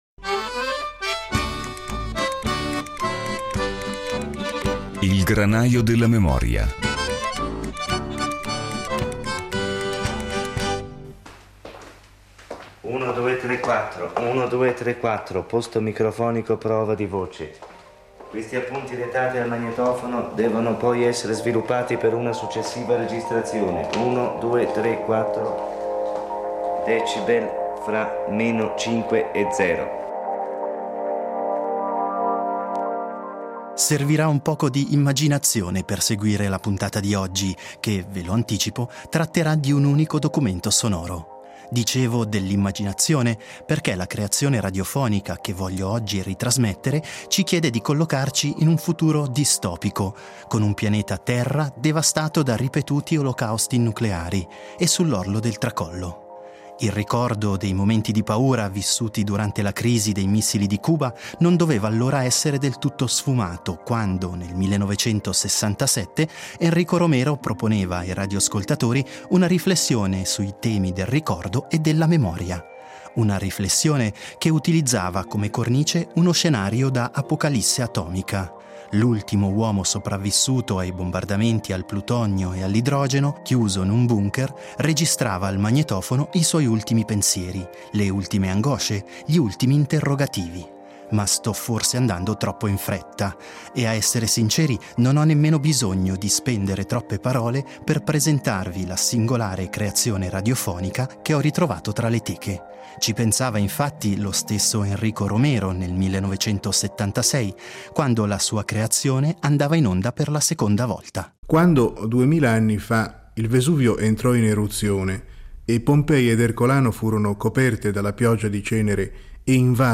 Il 12 settembre del 1967 il secondo programma della RSI trasmetteva un documentario intitolato La memoria del mondo .